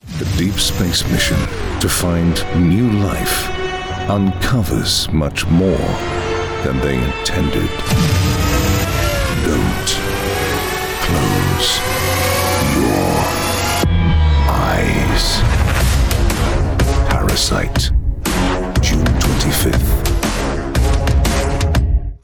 Anglais (britannique)
Bandes annonces
Aston Spirit microphone
Fully acoustic/sound treated recording environment